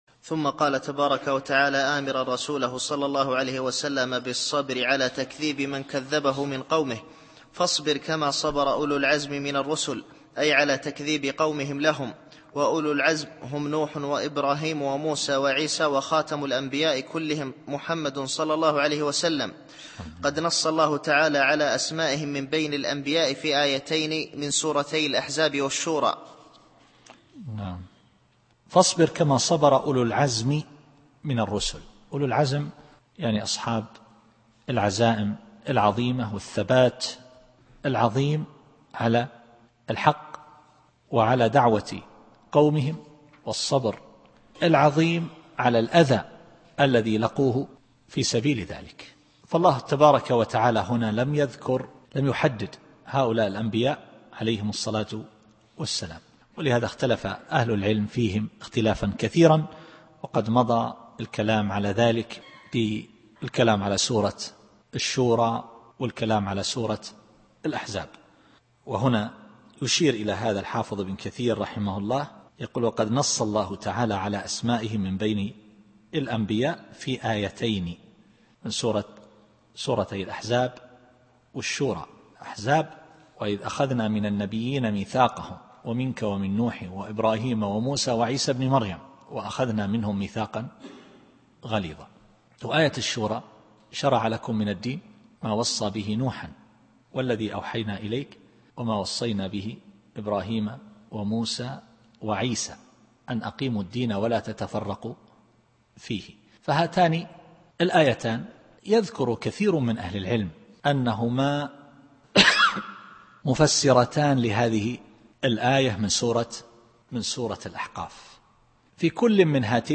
التفسير الصوتي [الأحقاف / 35]